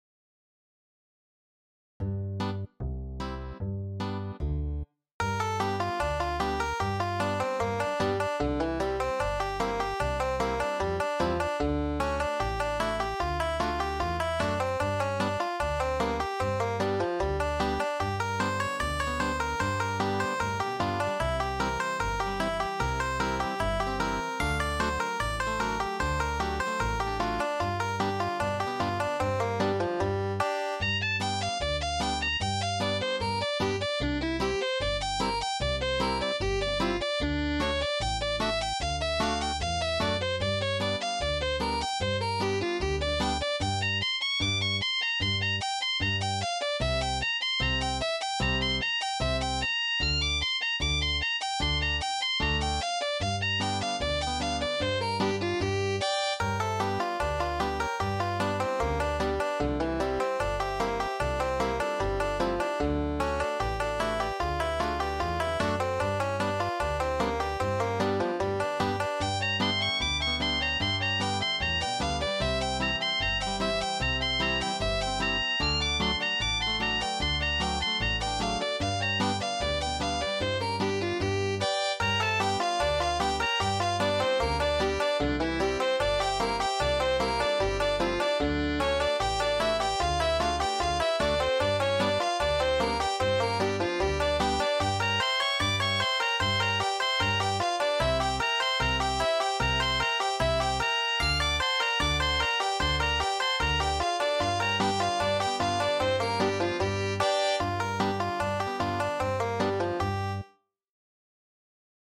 Bluesy Banjo
Adapted from a tune by Fred Sokolow
8-beat intro.
Parts 1 and 2 have the same chords but different melodies.
bluesy.mp3